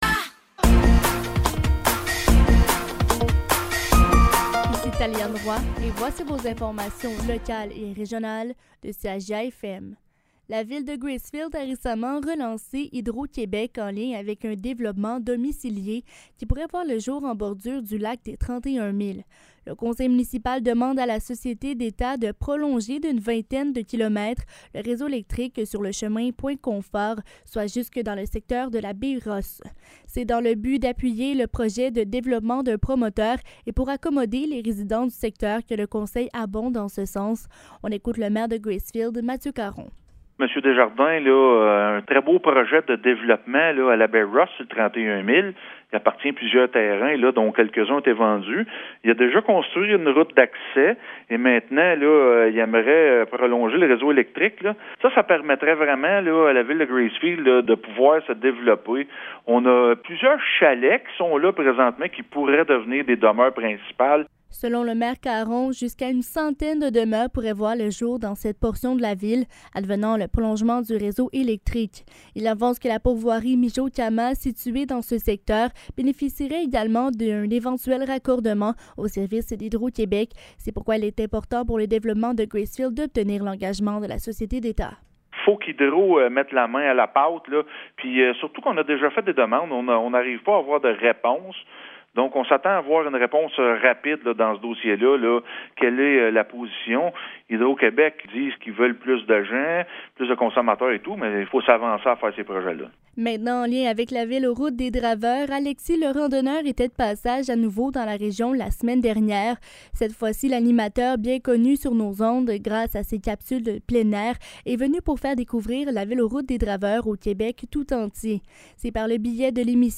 Nouvelles locales - 4 juillet 2023 - 15 h